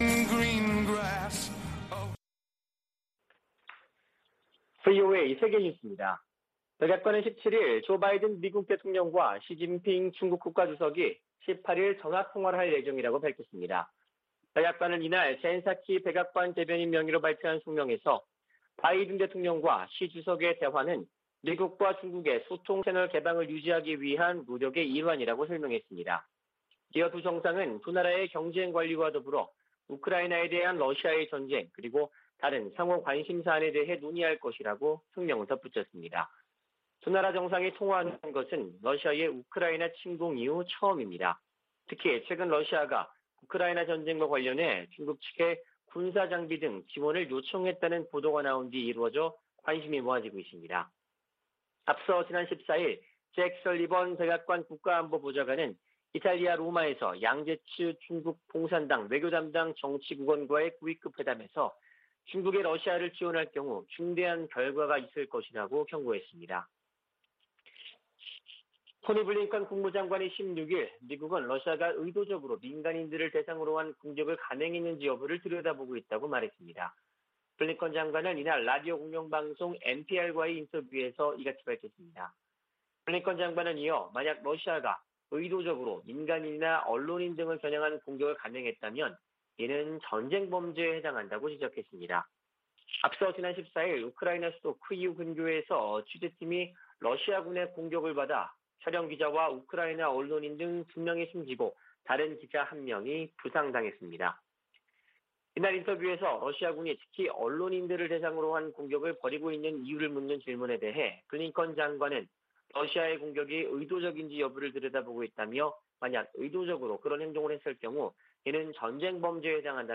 VOA 한국어 아침 뉴스 프로그램 '워싱턴 뉴스 광장' 2022년 3월 18일 방송입니다. 미군 당국은 한반도의 어떤 위기에도 대응 준비가 돼 있다며, 억지력 기초는 준비태세라고 강조했습니다. 북한은 16일 탄도미사일 발사 실패에 침묵하고 있습니다. 미국의 전문가들은 북한의 지속적인 미사일 발사에 미한이 억지력 강화에 주력하면서 한반도 긴장이 고조될 것이라고 관측했습니다.